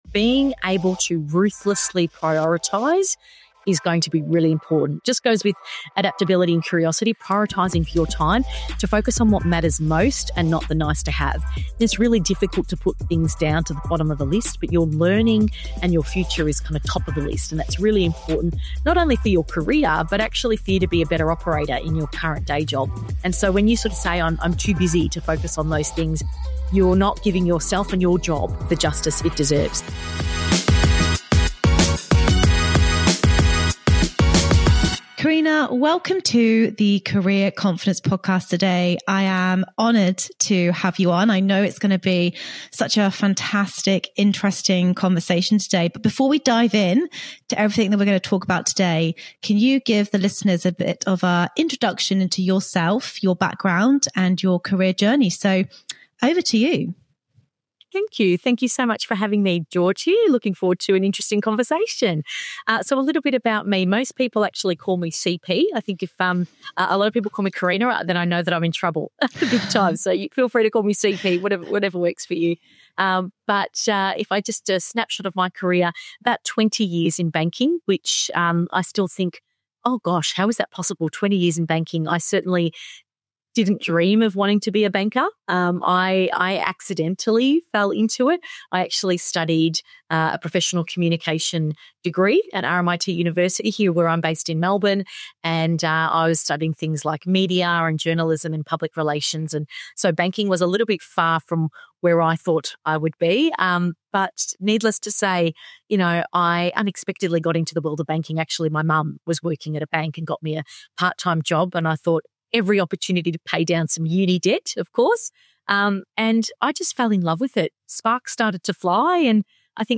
This conversation will change how you think about career security forever.